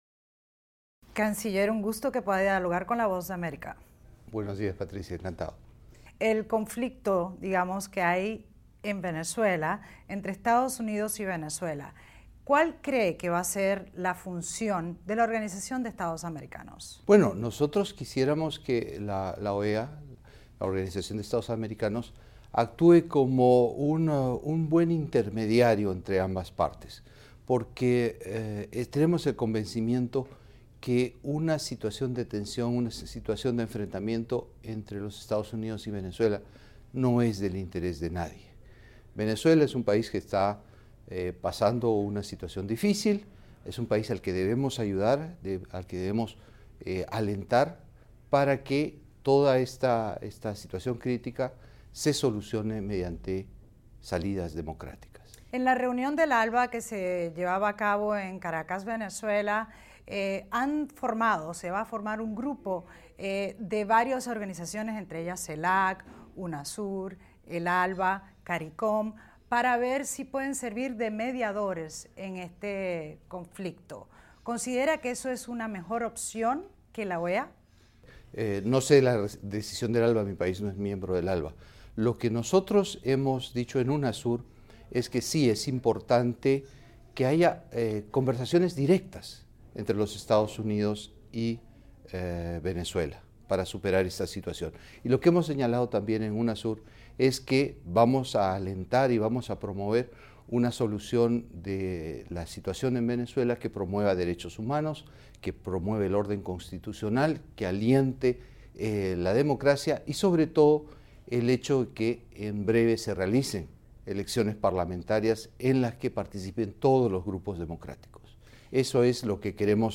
La Voz de América entrevista al canciller peruano, Gonzalo Gutiérrez sobre la declaración de Unasur sobre Venezuela y EE.UU., las relaciones de su país con Estados Unidos y los nuevos alcances en política exterior del país sudamericano.